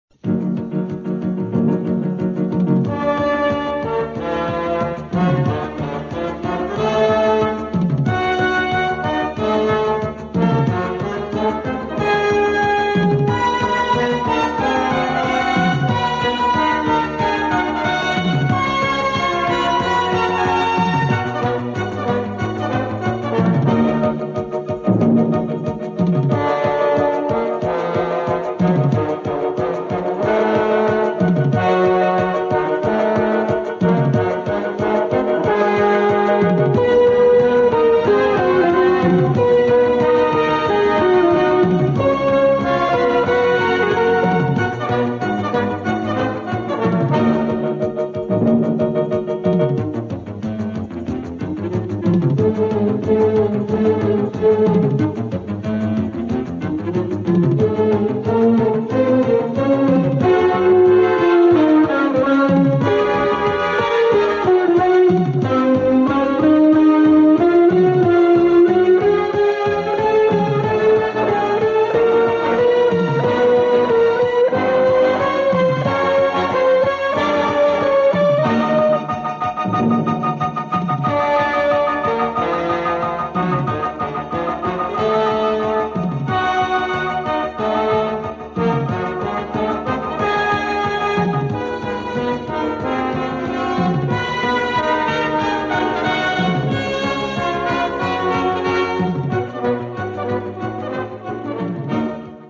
Главная / Computer & mobile / Мелодии / Саундтрек